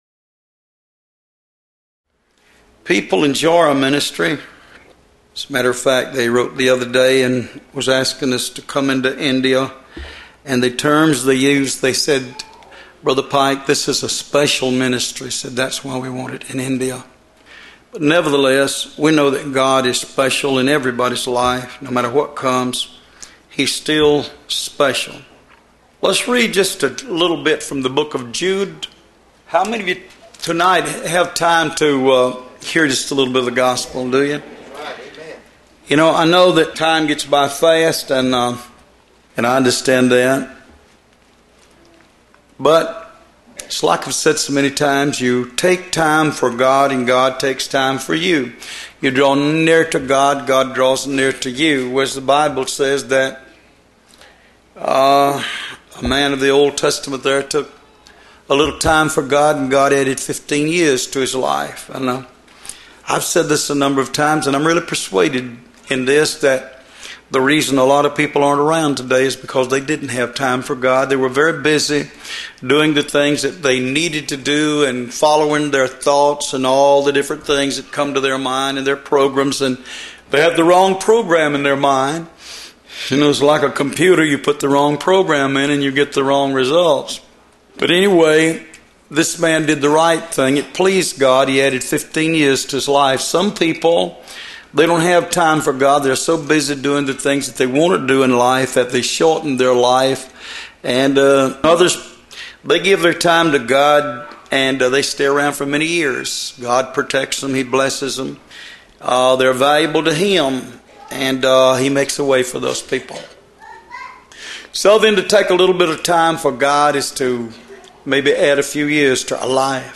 Sermons Starting With ‘M’